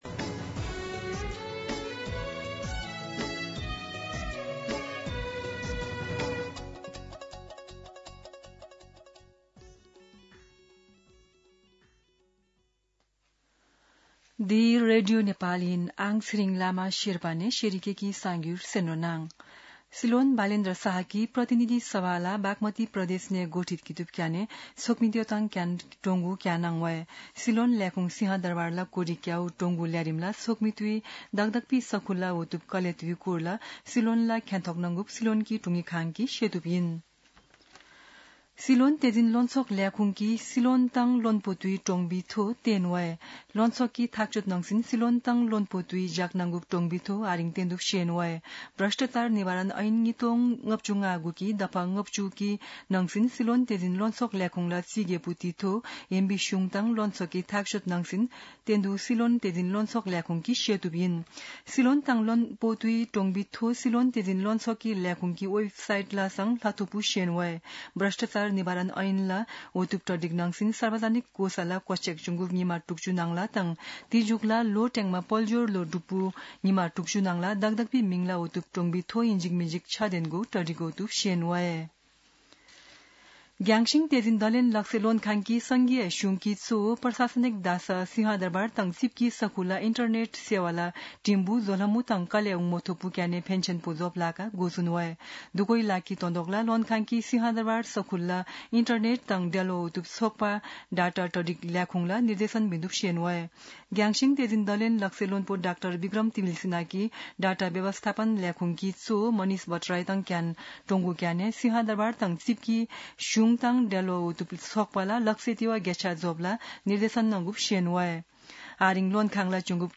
शेर्पा भाषाको समाचार : ३० चैत , २०८२
Sherpa-News-30.mp3